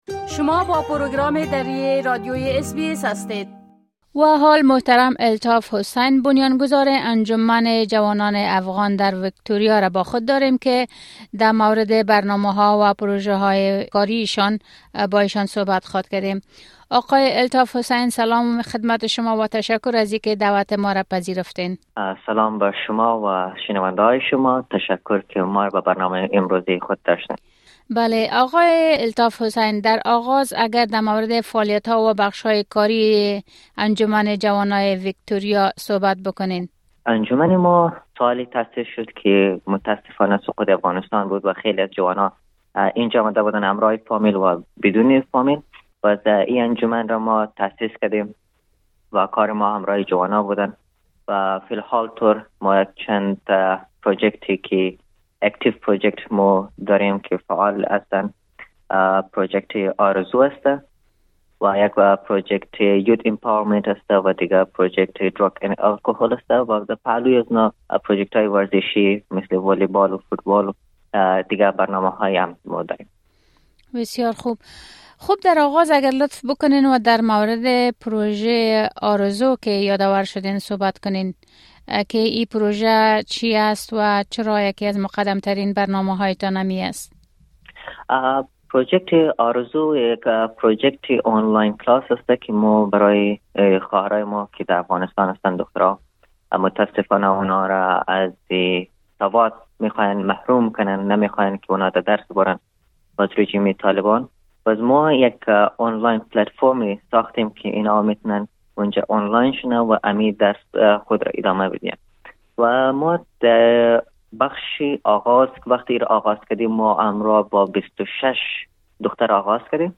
گفت‌وگوی اس‌بی‌اس دری